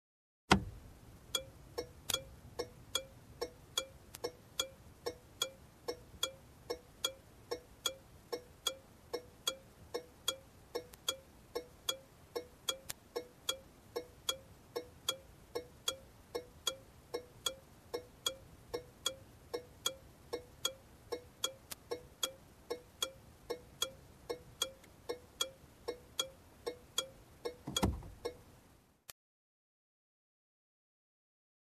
На этой странице собраны различные звуки поворотников автомобилей: от классических щелчков реле до современных электронных сигналов.
Звук включения долгая работа и выключение поворотников старого автомобиля